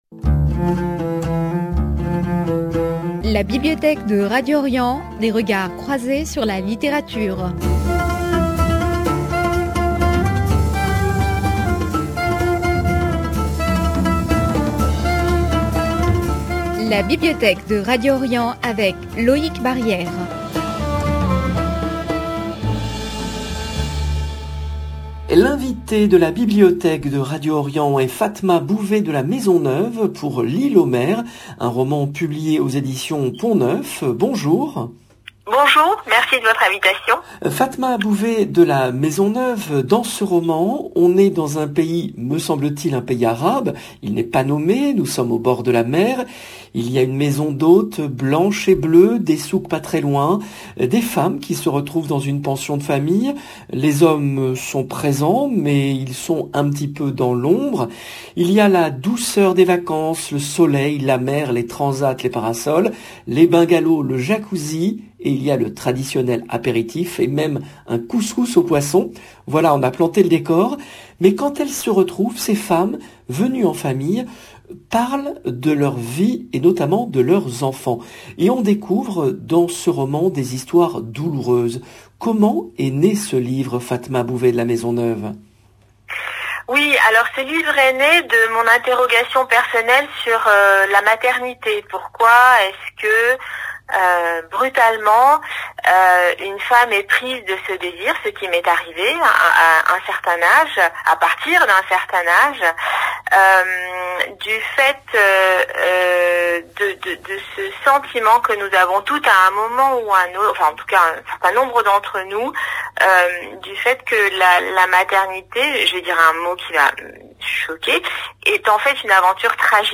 invitée de la Bibliothèque de Radio Orient